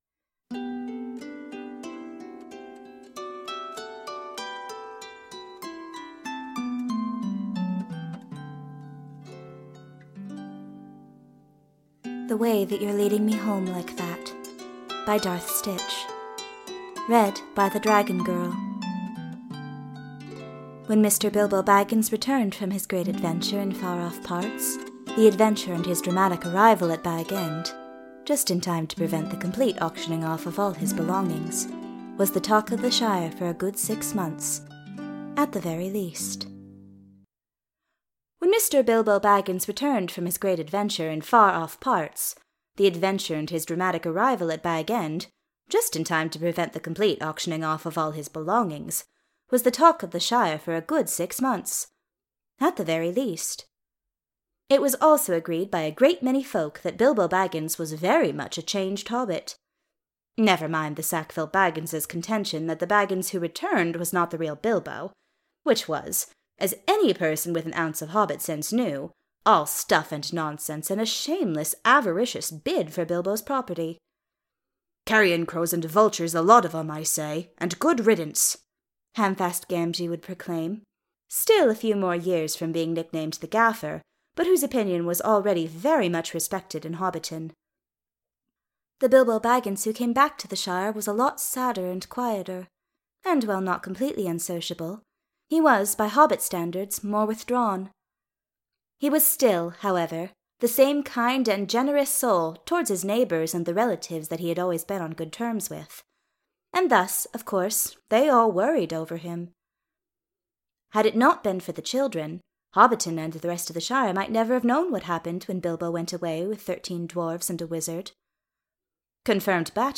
The Way That You're Leading Me Home Like That [Podfic]